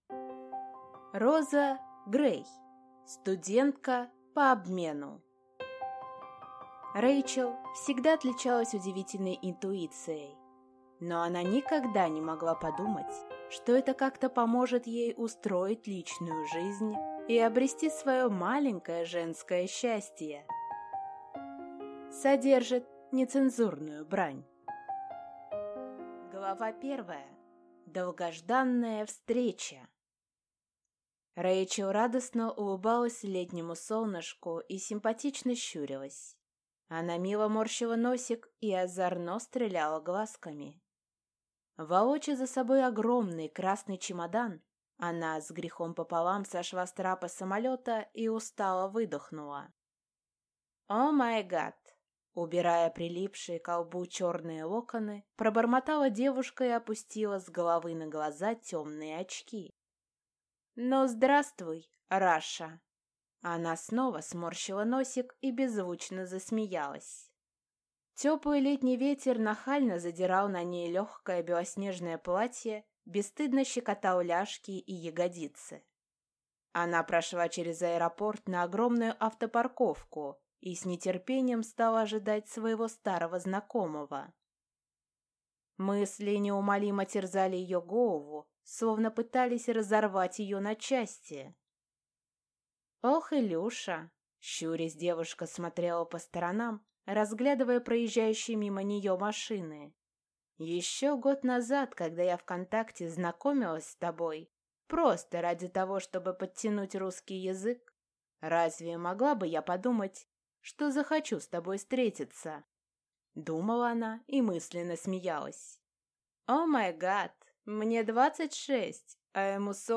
Аудиокнига Студентка по обмену | Библиотека аудиокниг
Прослушать и бесплатно скачать фрагмент аудиокниги